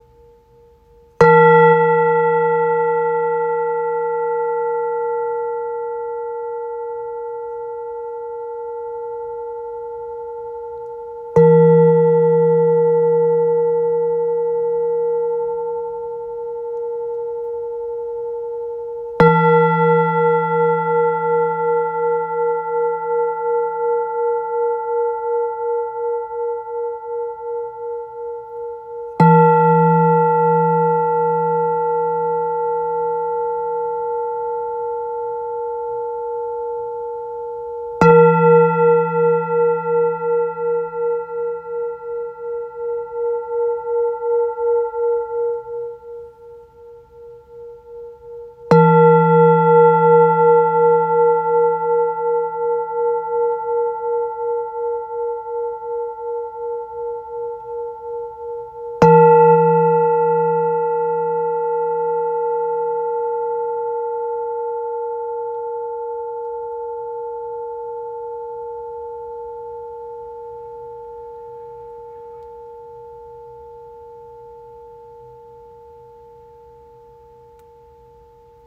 “シンギングボウル”。